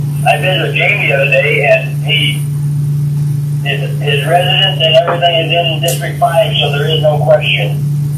The Walworth County Commission held their last meeting of 2021 on Thursday (Dec. 30th).
Commissioner Houck, on speaker phone, told the board there is no issue with Jungwirth representing District 5.